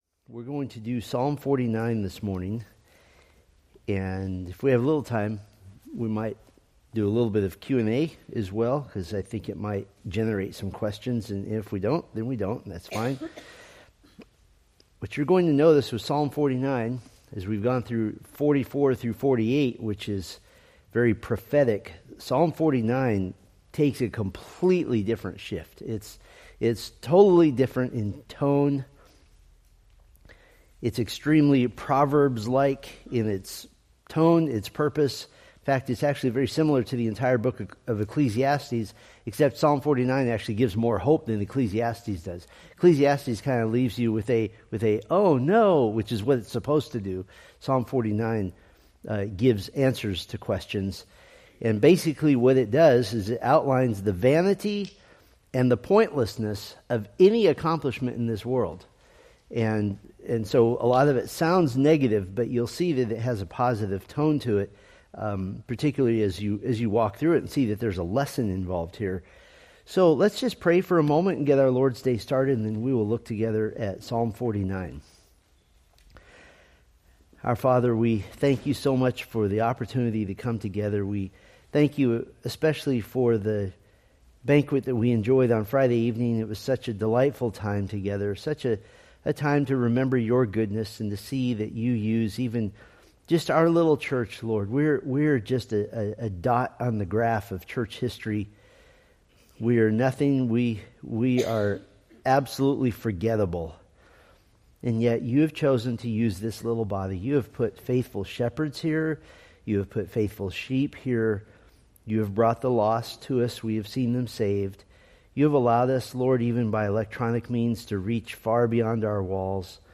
Date: Feb 1, 2026 Series: Psalms Grouping: Sunday School (Adult) More: Download MP3 | YouTube